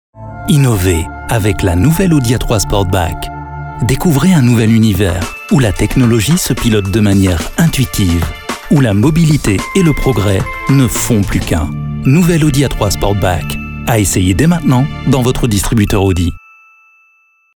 spot publicitaire
élégant , posé